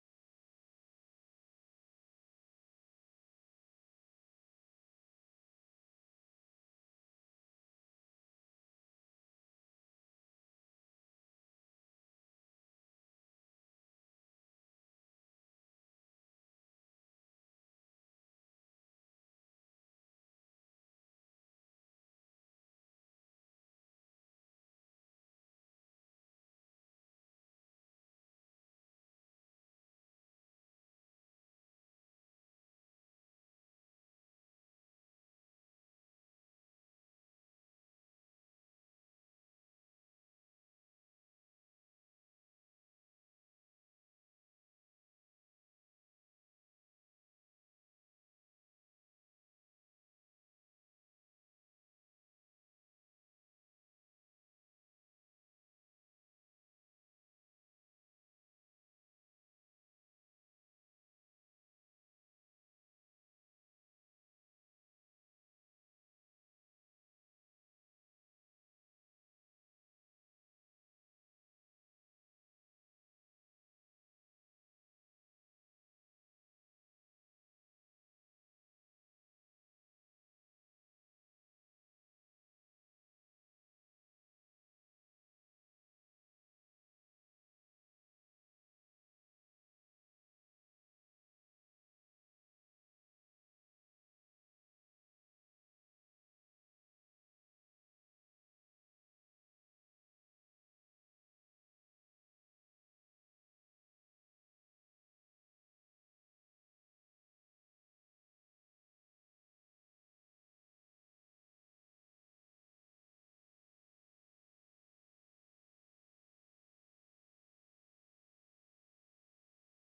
7:30 A.M. Service: Spiritual Veterans